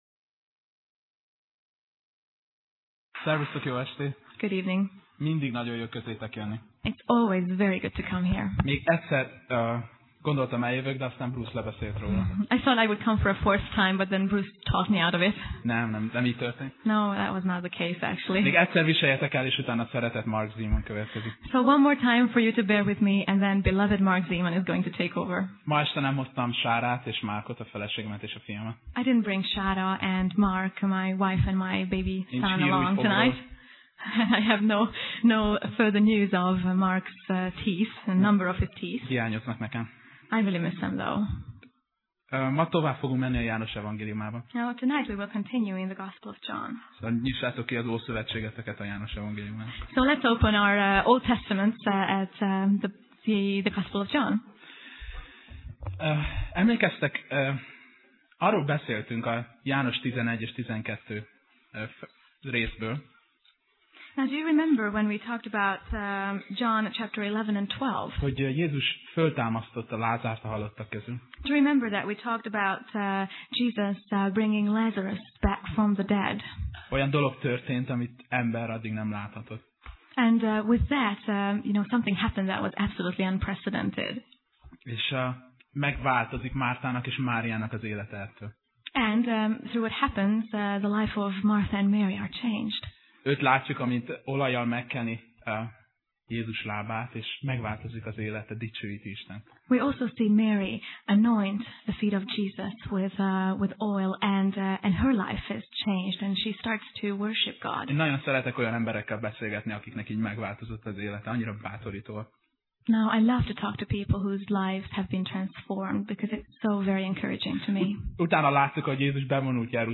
Sorozat: Tematikus tanítás
Alkalom: Szerda Este